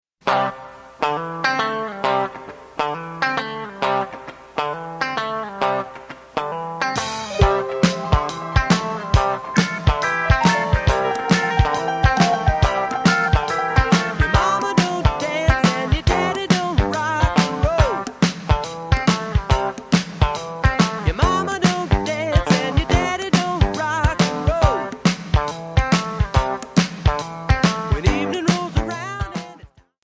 Backing track files: Rock (2136)